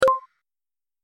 دانلود صدای کلیک 15 از ساعد نیوز با لینک مستقیم و کیفیت بالا
جلوه های صوتی